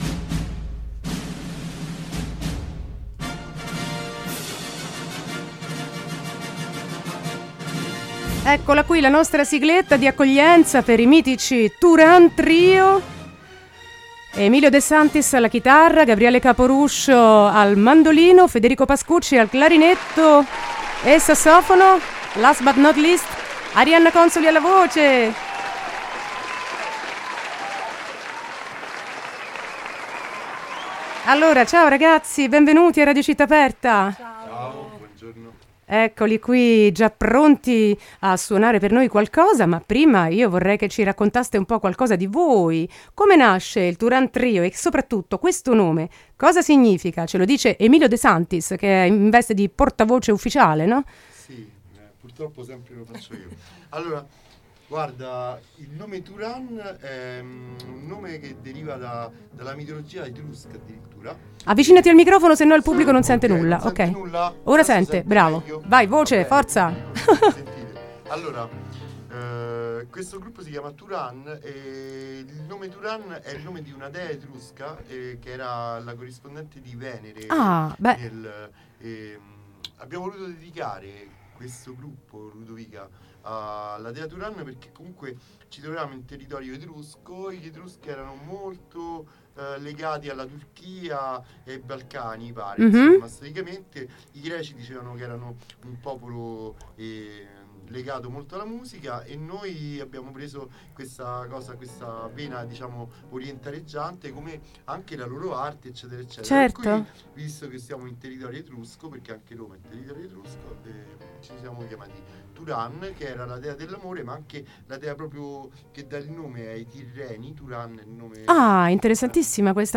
Intervista e minilive Turan Trio 28-11-19
chitarra
mandolino
sax e clarinetto
voce